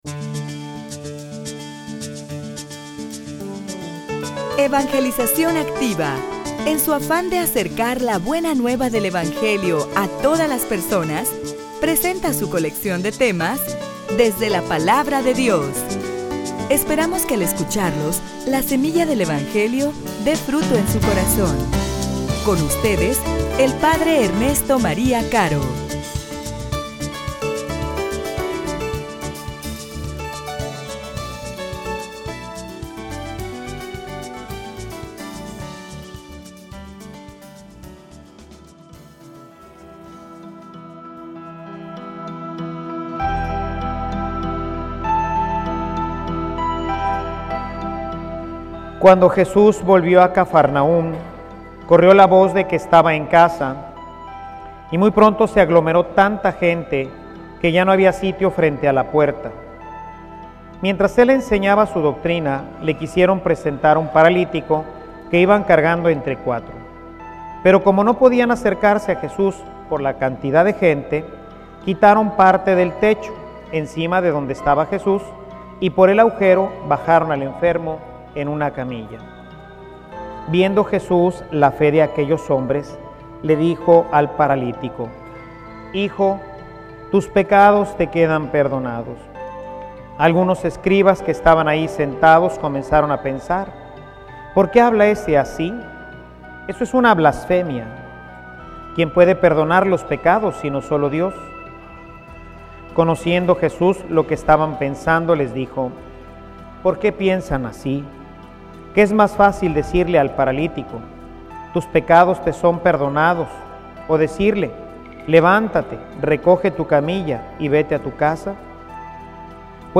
homilia_Con_amor_eterno_te_ame.mp3